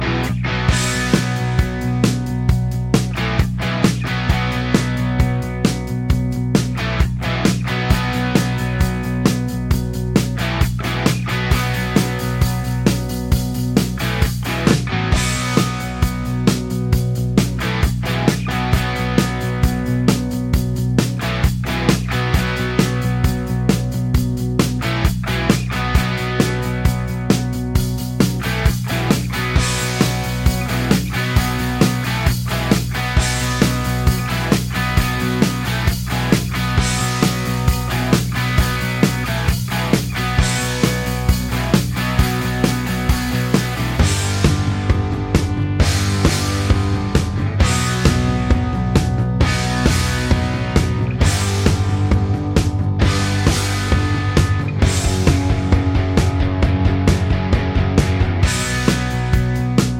no Backing Vocals Rock 5:52 Buy £1.50